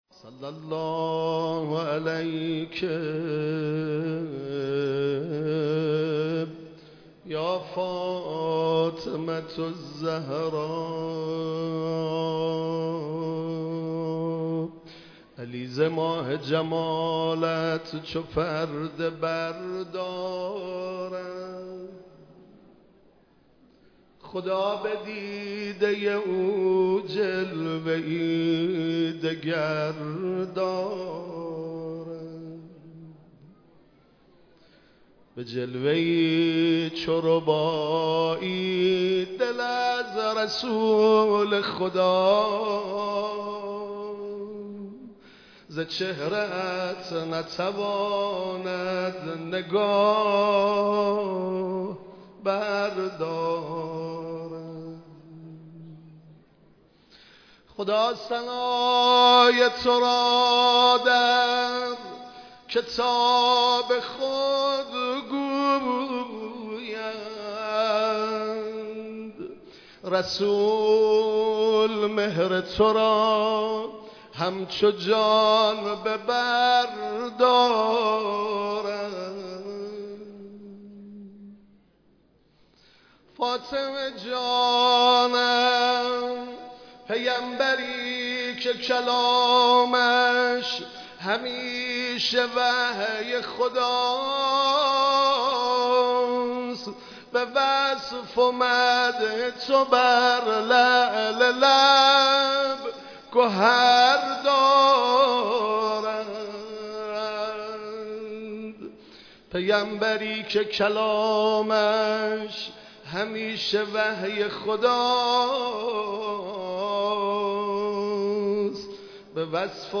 چهارمین شب مراسم عزاداری حضرت صدیقه کبری(س) در حسینیه امام خمینی(ره) برگزار شد
مداحی